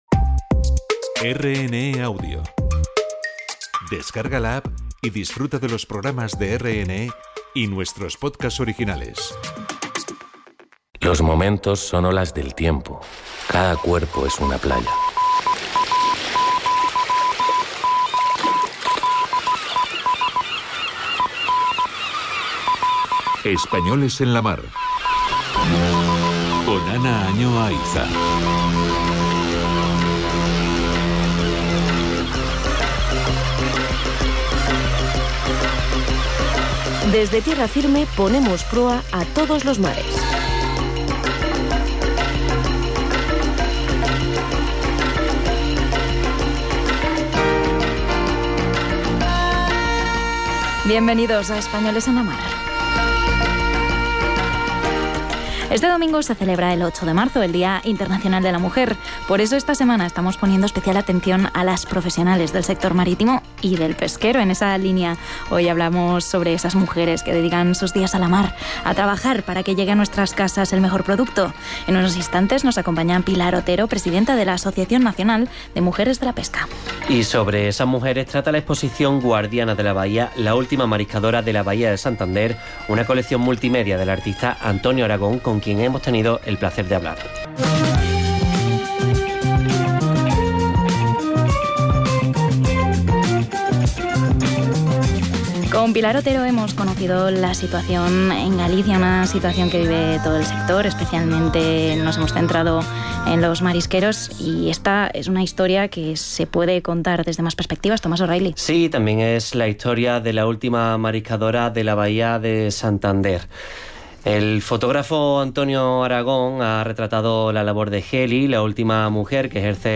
RNE-Espanoles-en-la-Mar-Entrevista.mp3